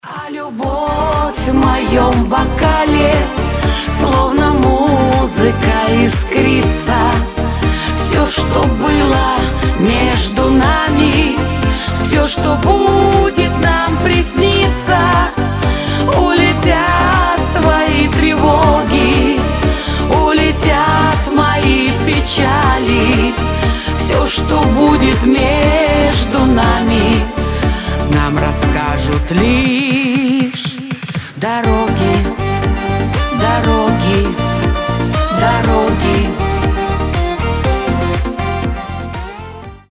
• Качество: 320, Stereo
женский голос